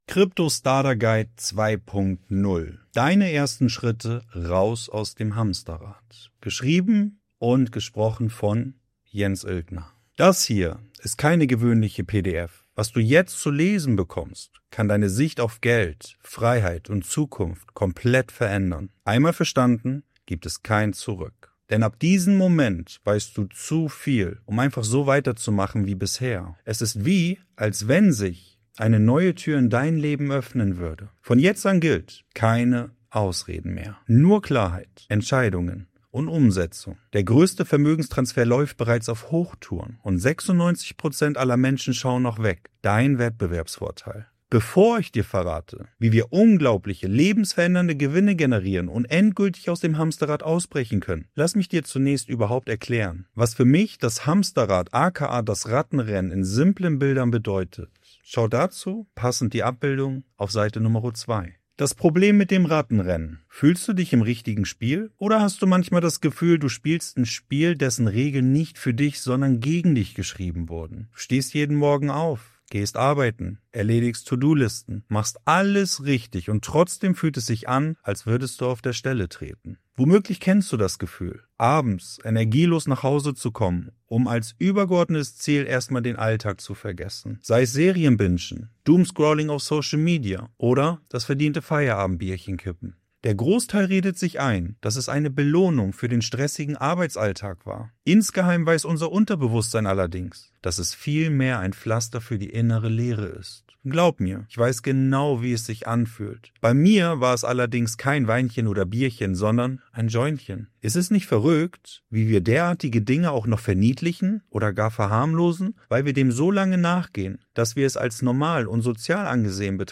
PDF downloaden PDF downloaden Hörbuch downloaden Hörbuch downloaden Einige häufig gestellte Fragen beantwortet...